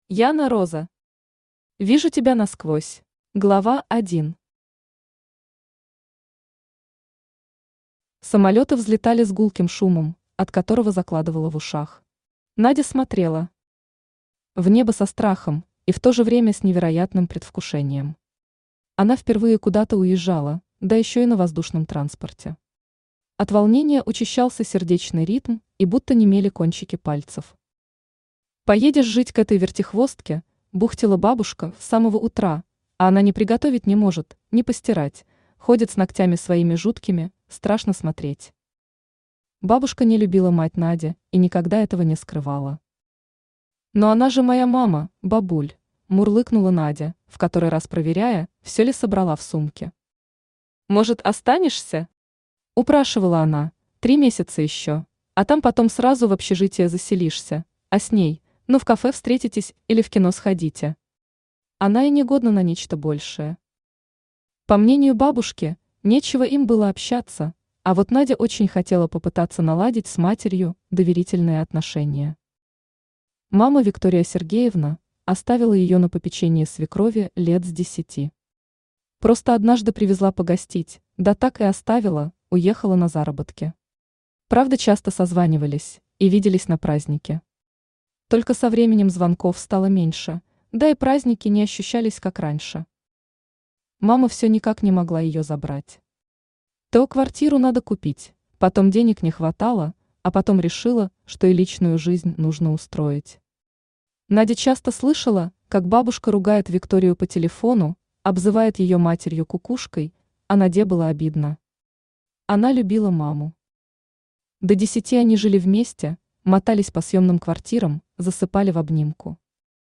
Аудиокнига Вижу тебя насквозь | Библиотека аудиокниг
Aудиокнига Вижу тебя насквозь Автор Яна Роза Читает аудиокнигу Авточтец ЛитРес.